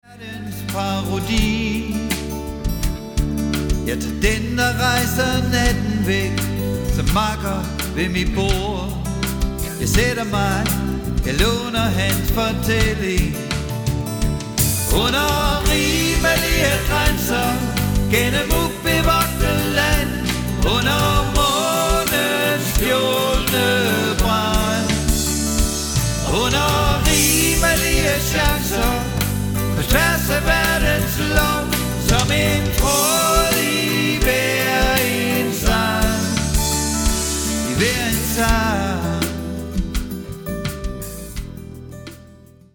Trommer, percussion og sang.
Bas og sang.
Guitar, keyboards og sang.
El-guitar og sang.